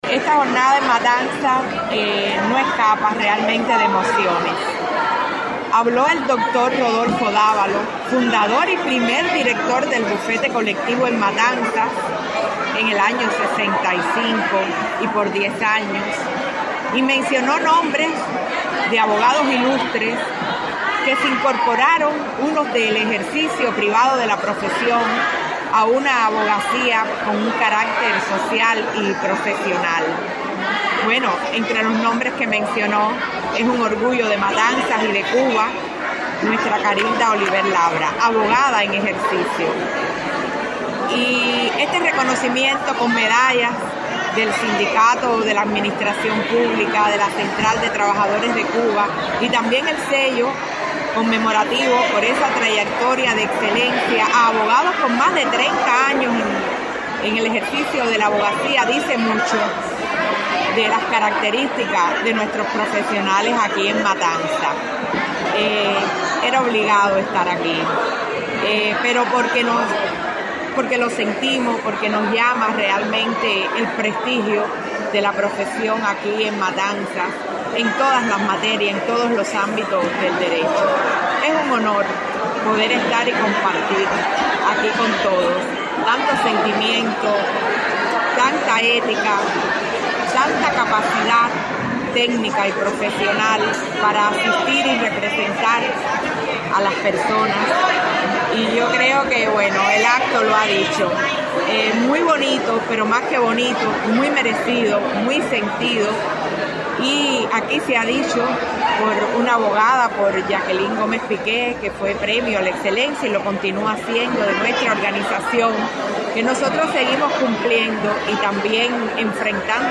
El salón de los espejos del Teatro Sauto fue el escenario elegido para efectuar la ceremonia de entrega de reconocimientos y condecoraciones a destacados profesionales del Derecho que se desempeñan en el ejercicio de la abogacía, así como evocar la fecha fundacional del primer bufete colectivo en Cuba, instituido el 22 de enero de 1965.